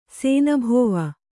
♪ sēnabhōva